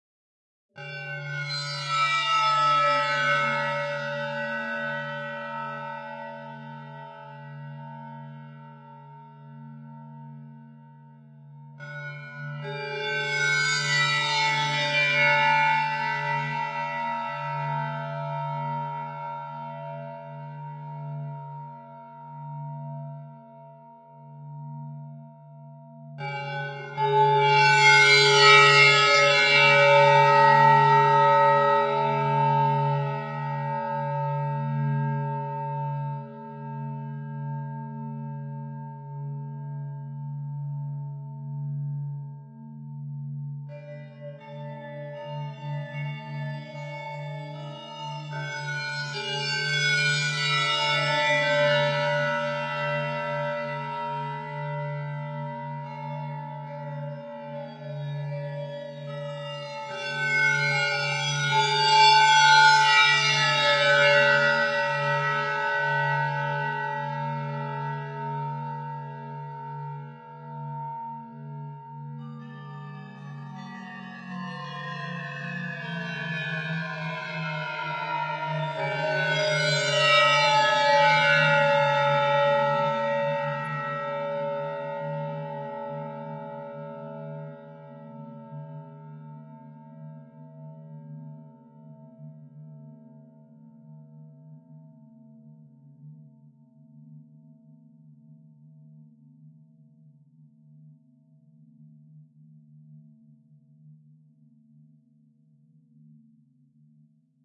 描述：softsynth
标签： synthesizer